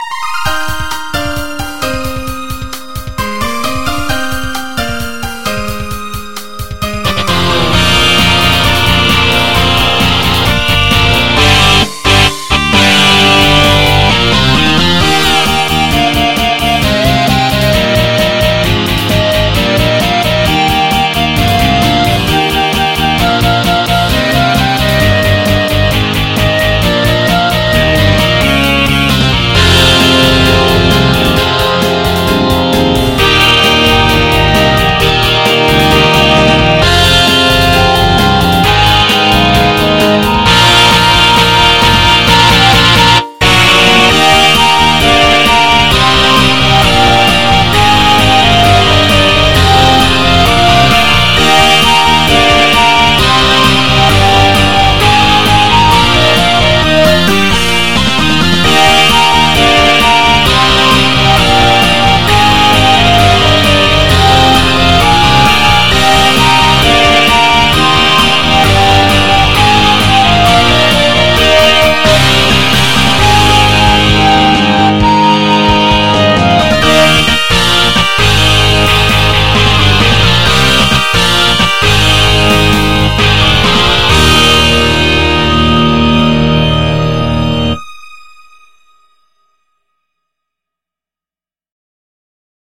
MIDI 24.78 KB MP3 (Converted) 1.42 MB MIDI-XML Sheet Music
for the Roland VSC-3 and the SGM V2.01 soundfont